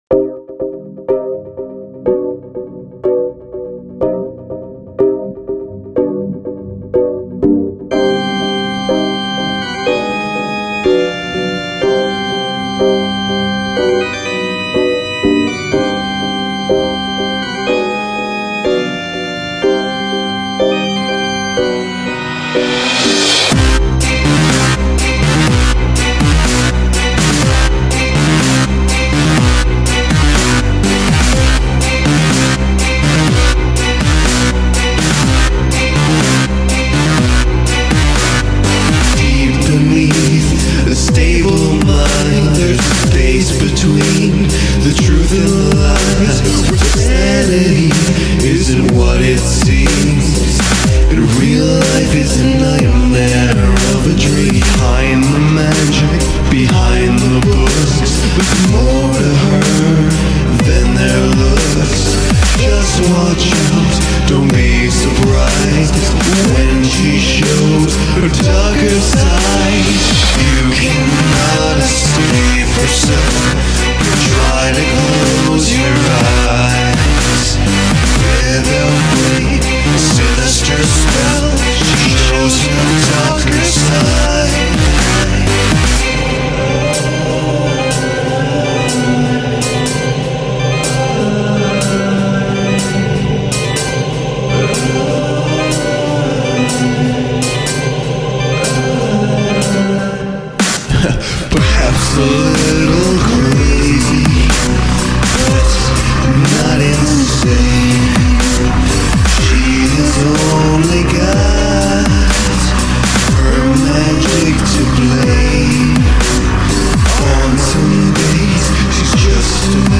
I don't do grimdark very well. Or dubstep.